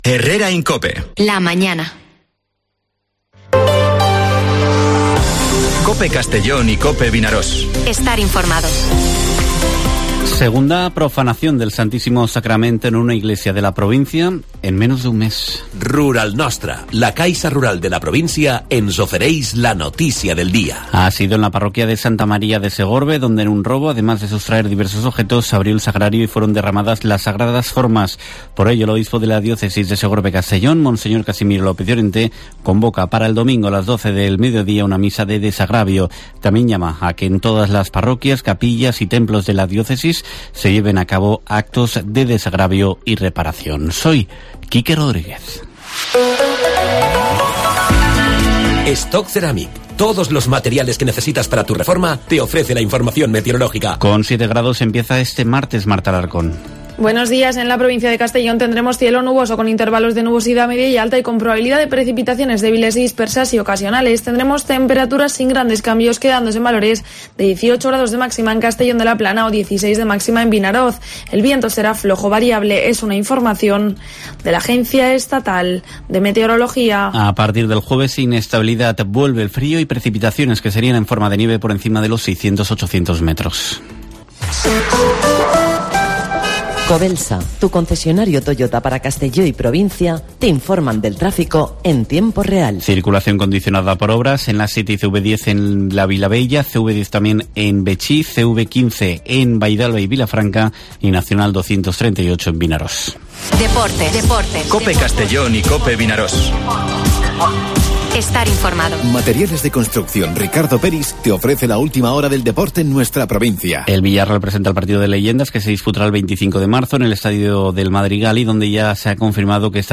Informativo Herrera en COPE en la provincia de Castellón (21/02/2023)